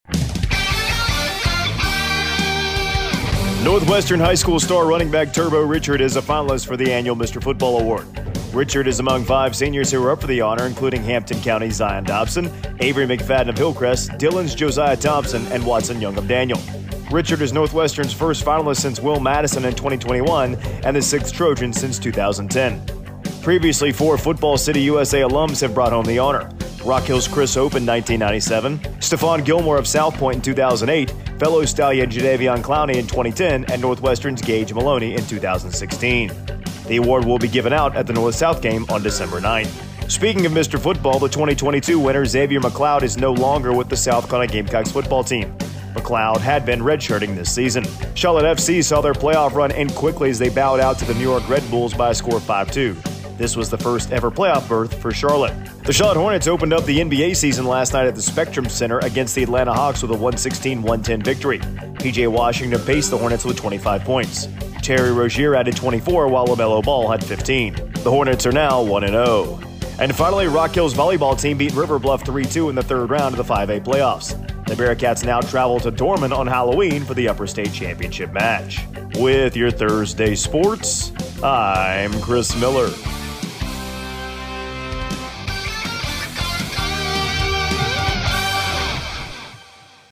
AUDIO: Wednesday Morning Sports Report